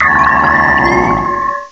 direct_sound_samples
cry_not_bronzong.aif